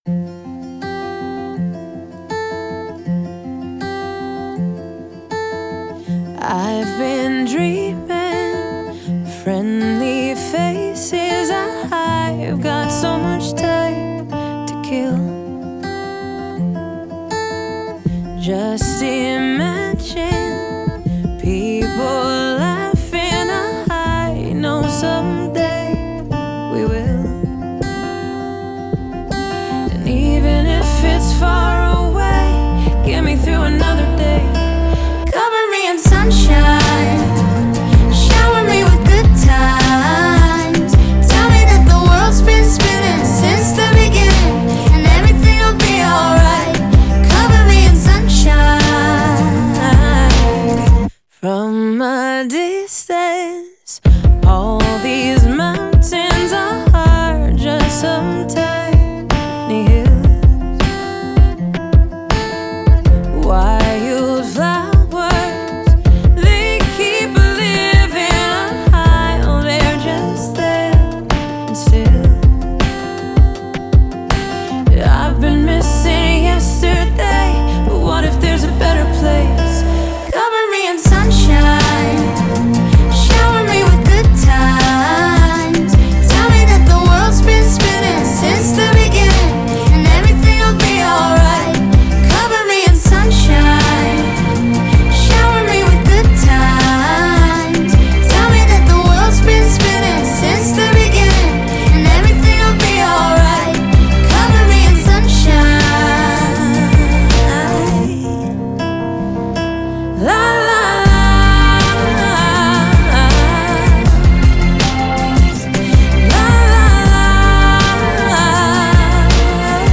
яркая и жизнерадостная песня